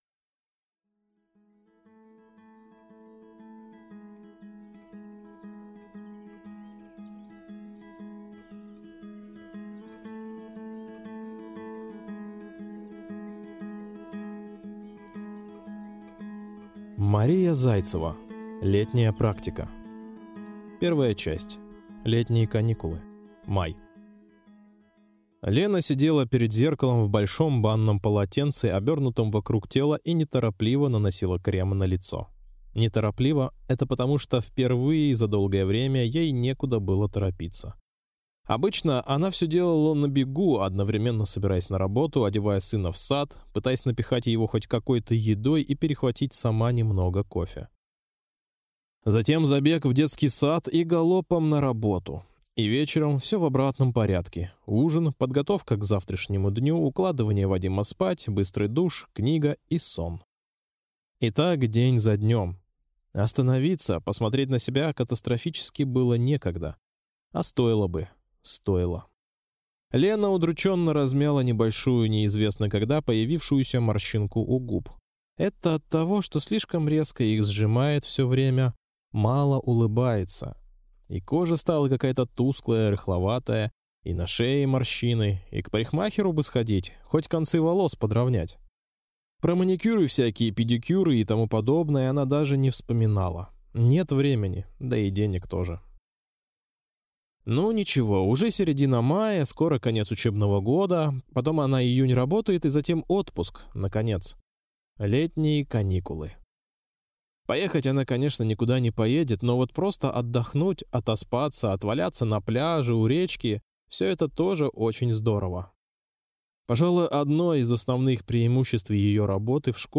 Аудиокнига Летняя практика | Библиотека аудиокниг